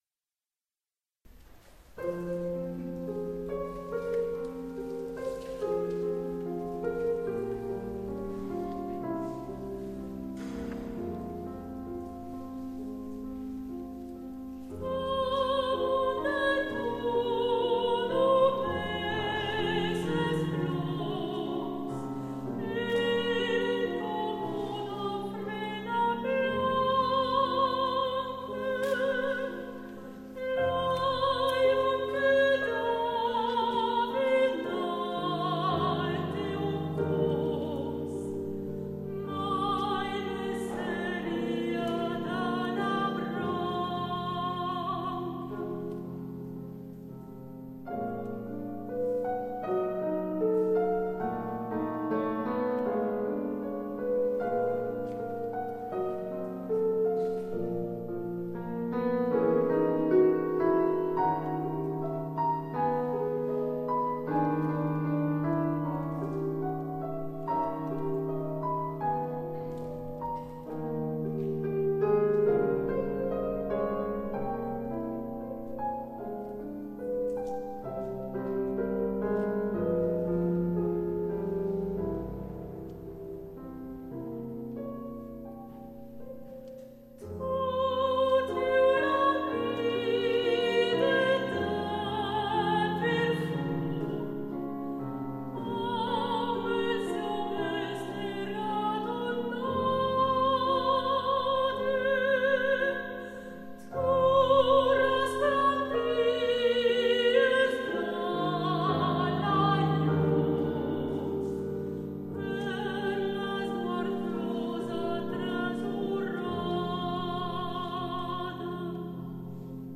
Chanteuse
piano
enregistré en concert